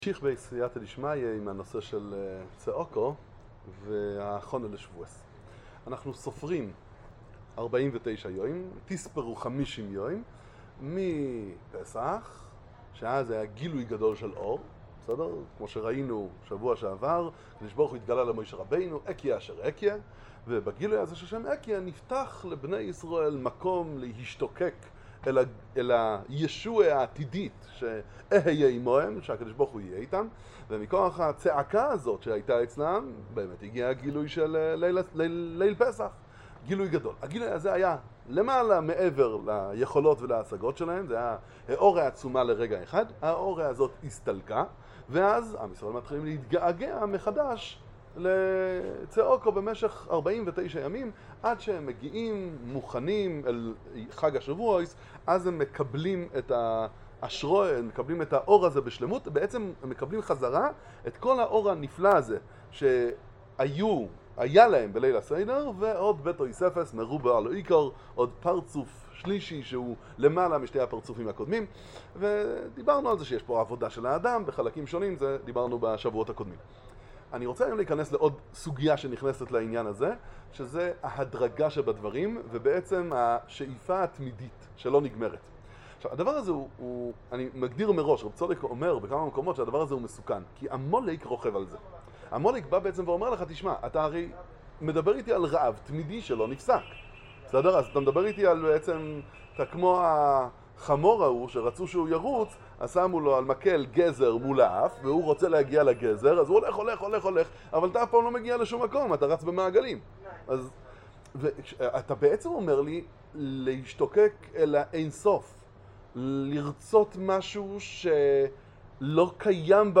שיעורים בספרי איזביצה ראדזין לובלין